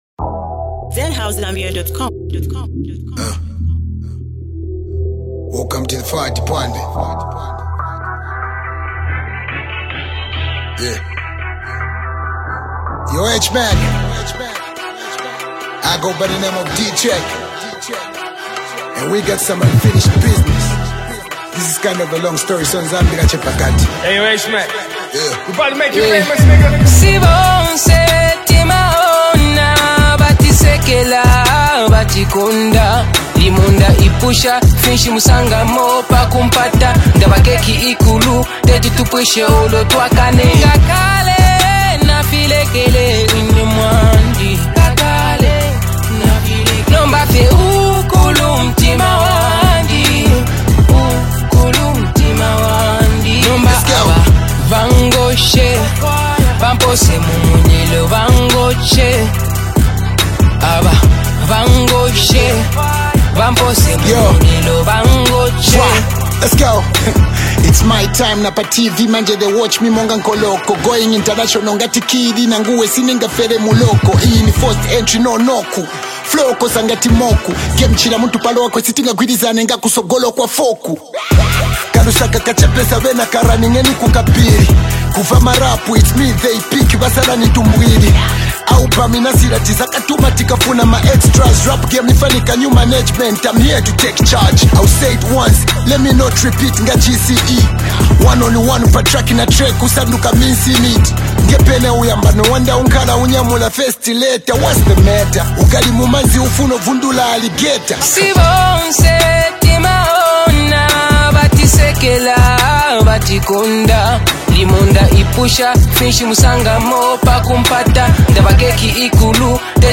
energetic anthem